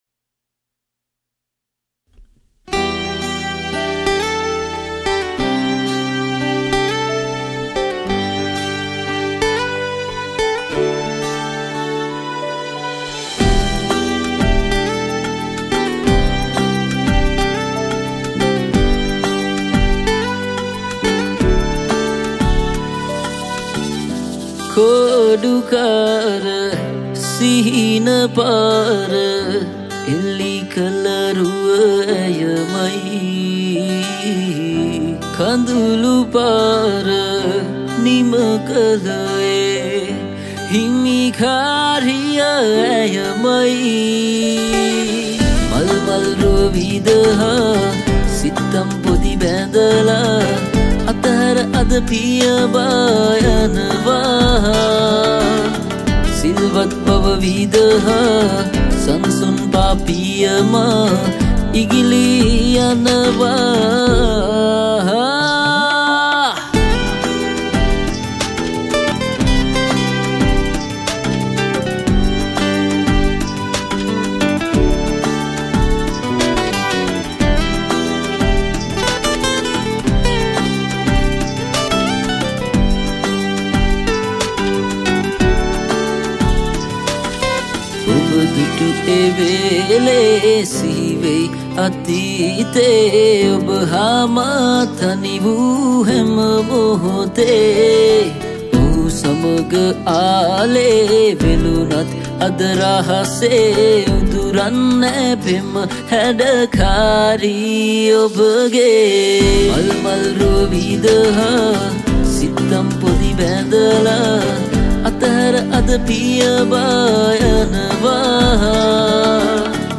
High quality Sri Lankan remix MP3 (4).
remix